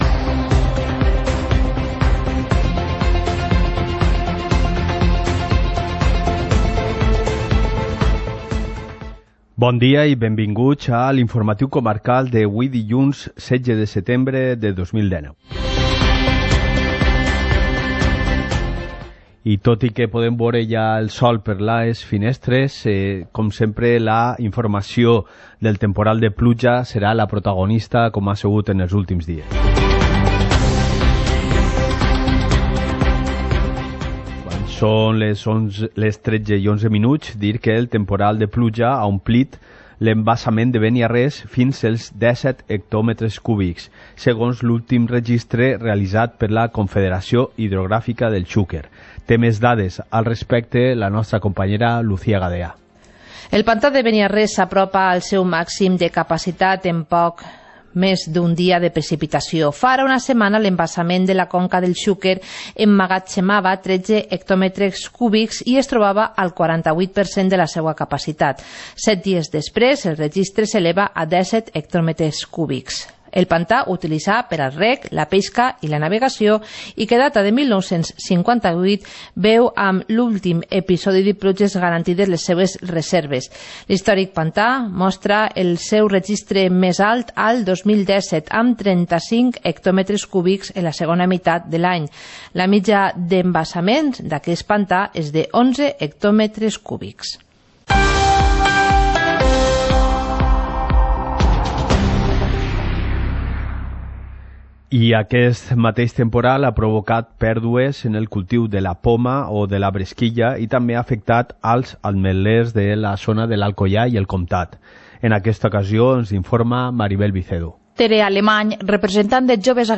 Informativo comarcal - lunes, 16 de septiembre de 2019